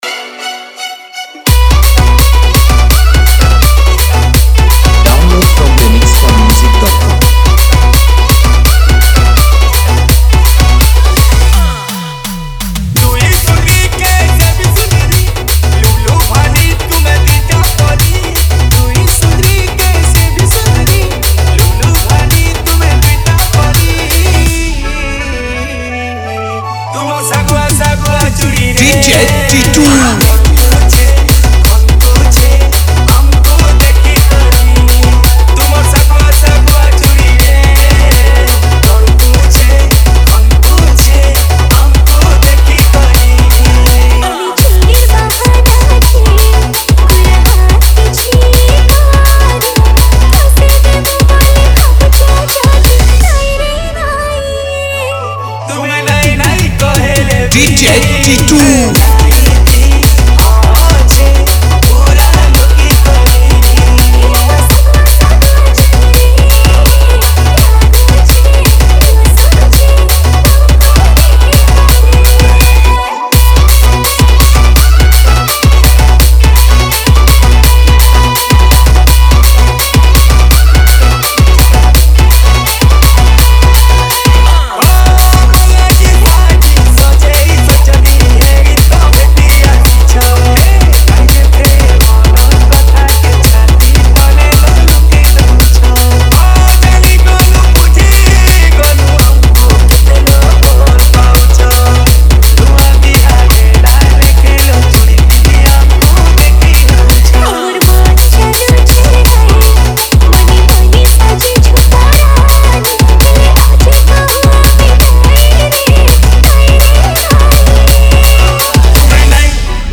Category : Odia Remix Song